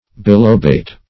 Bilobate \Bi*lo"bate\ (b[-i]*l[=o]"b[asl]t or b[imac]"l[-o]*b[asl]t)